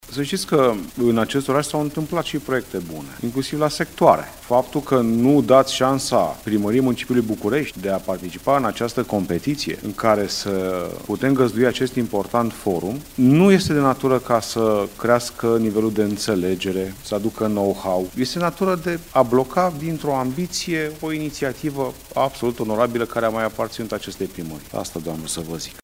Primarul general Ciprian Ciucu: „În acest oraș s-a întâmplat și proiecte bune”